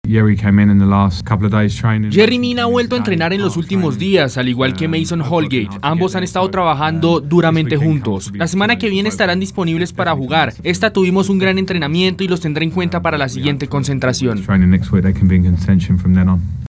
(Frank Lampard, técnico del Everton)